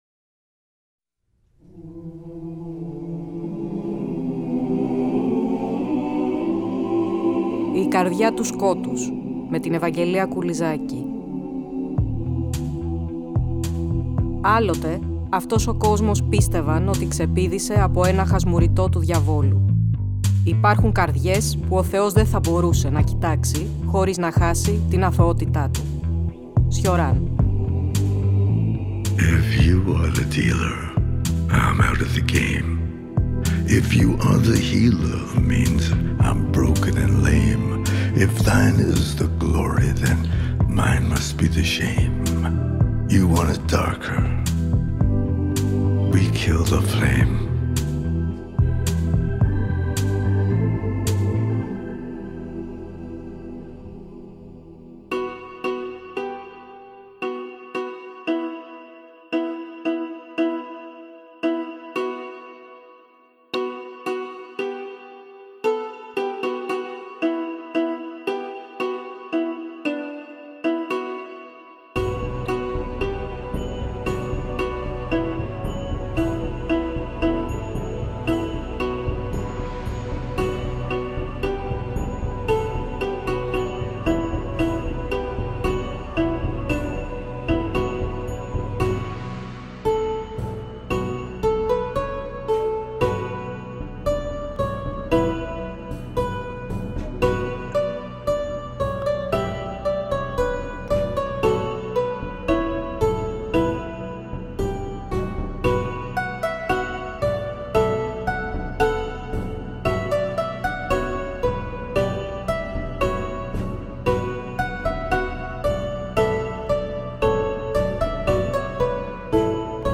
Ακούστε το 2ο επεισόδιο ενός νέου Κύκλου της εκπομπής, που μεταδόθηκε την Κυριακή 01 Μαρτίου από το Τρίτο Πρόγραμμα.